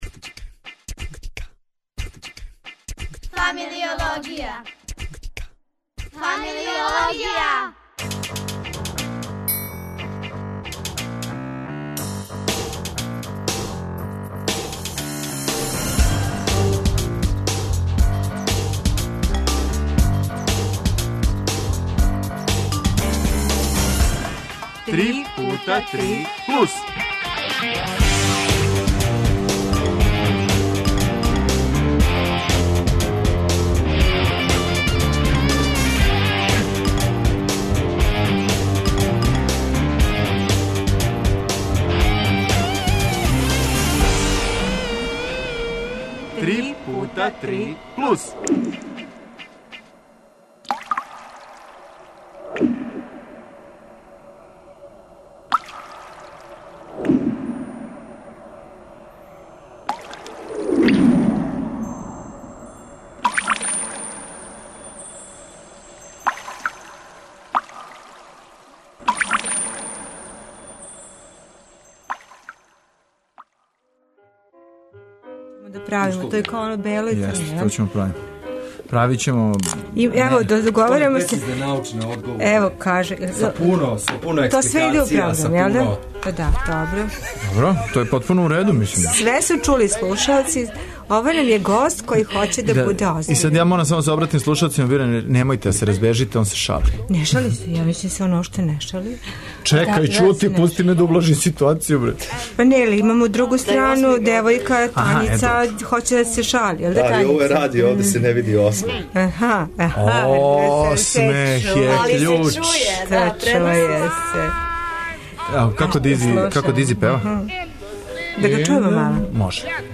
Гости су млади људи који умеју да Освоје простор (слободе? знања? креативности...)...